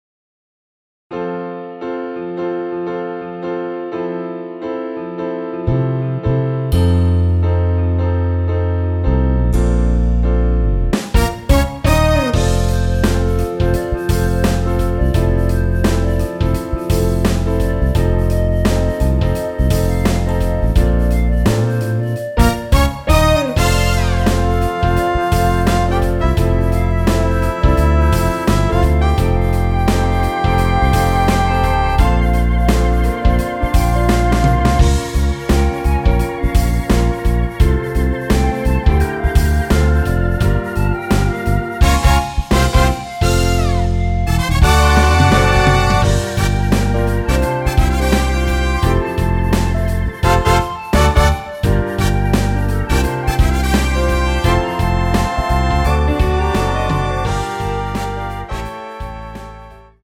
원키에서(+4)올린 멜로디 포함된 MR입니다.
앞부분30초, 뒷부분30초씩 편집해서 올려 드리고 있습니다.
중간에 음이 끈어지고 다시 나오는 이유는